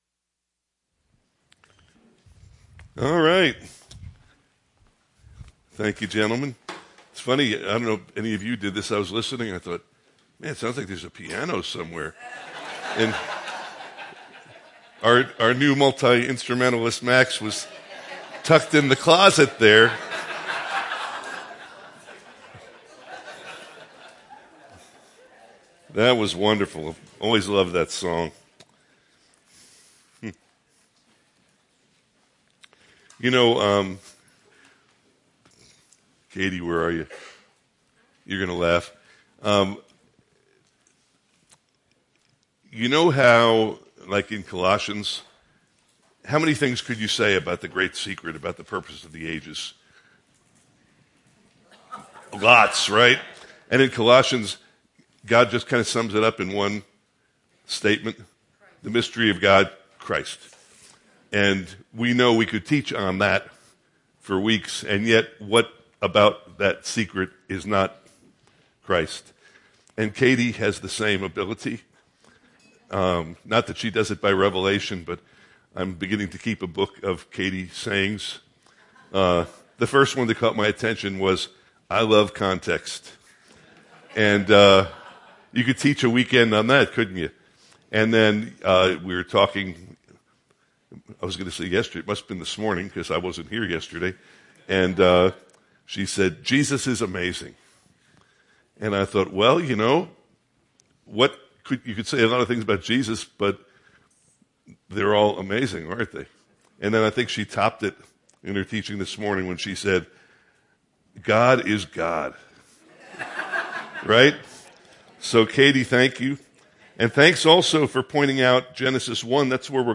Part 4 of 5 in a series of weekend teachings on the topic of God, Our Creator. God created the world and every creature in it.